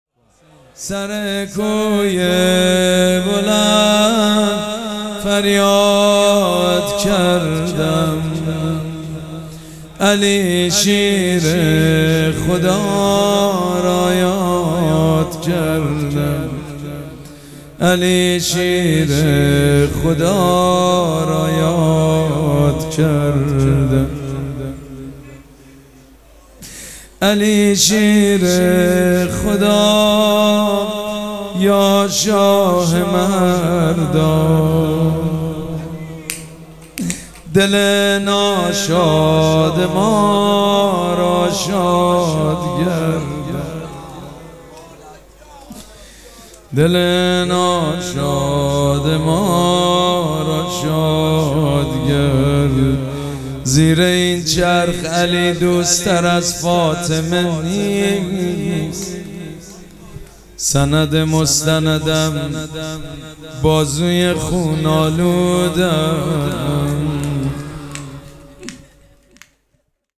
شب پنجم مراسم عزاداری اربعین حسینی ۱۴۴۷
زمزمه
مداح
حاج سید مجید بنی فاطمه